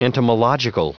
Prononciation du mot entomological en anglais (fichier audio)
Prononciation du mot : entomological